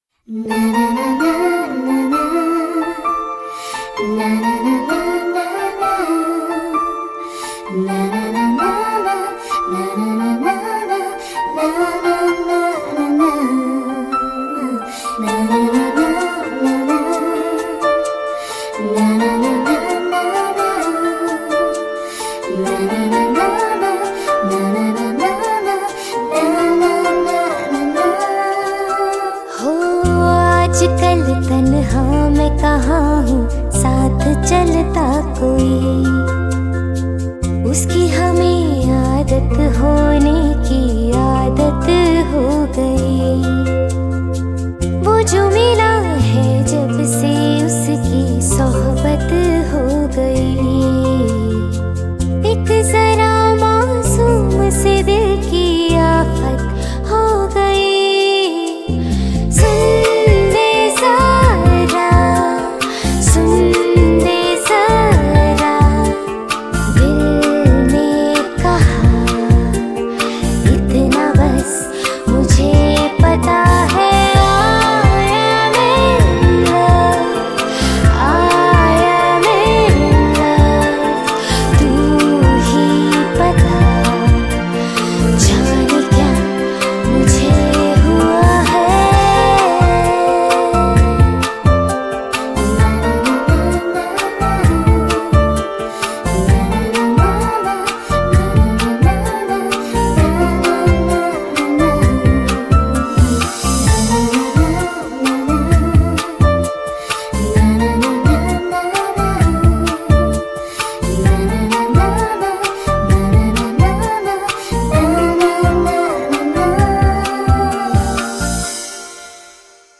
Bollywood